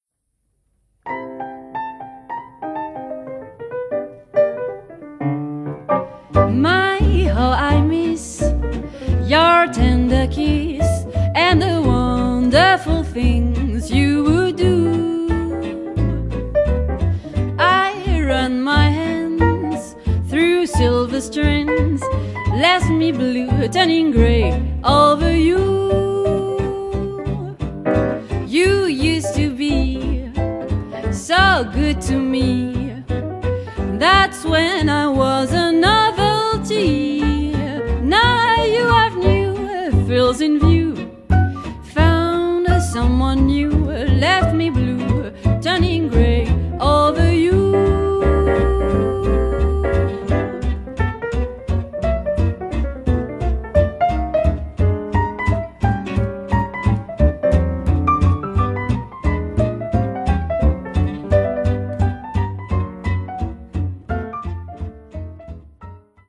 chant
piano
guitare
contrebasse.